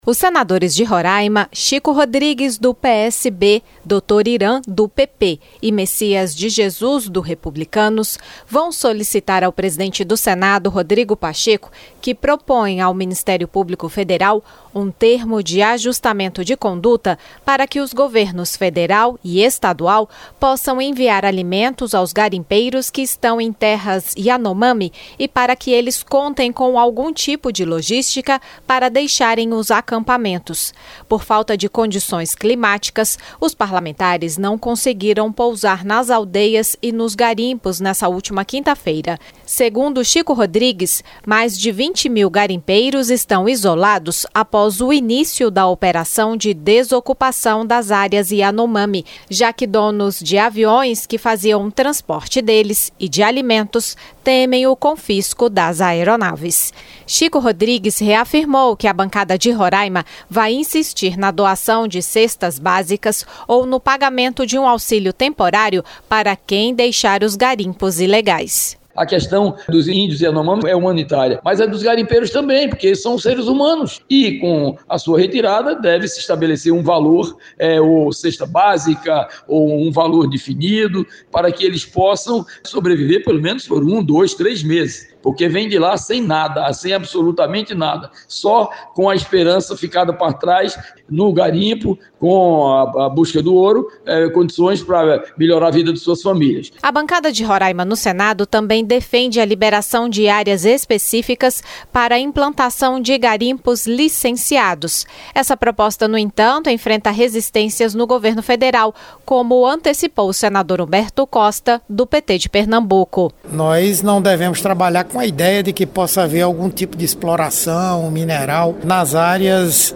Mas a proposta de liberação de áreas para a exploração mineral legal encontra resistências do governo, como destacou o senador Humberto Costa (PT-PE).